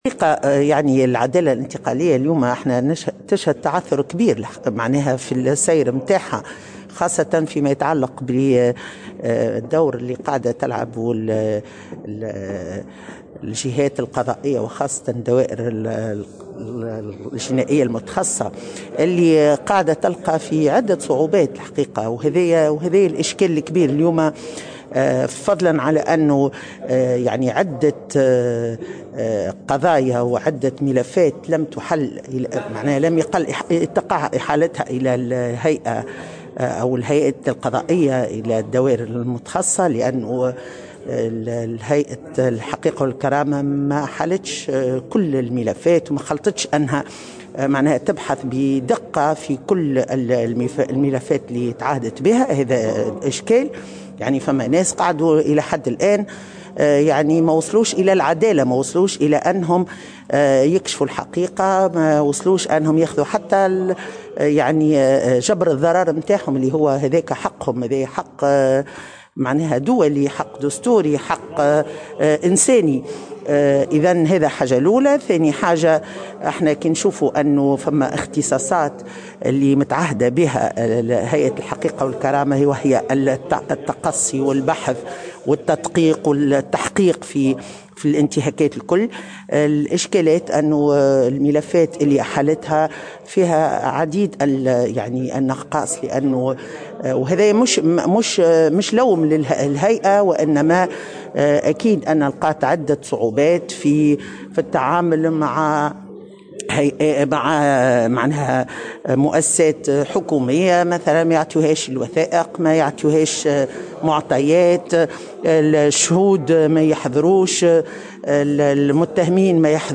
أكدت القاضية السابقة ورئيس مكتب اللجنة الدولية للحقوقيين بتونس كلثوم كنو اليوم الإثنين خلال ندوة صحفية تم تنظيمها لتقديم تقرير بعنوان"لا مصالحة دون محاسبة تقييم الدوائر الجنائية المتخصصة في تونس وآفاقها" أن العدالة الإنتقالية تشهد تعثرا كبيرا في سيرها خاصة فيما يتعلق بدور الجهات القضائية وخاصة الدوائر الجنائية المتخصصة.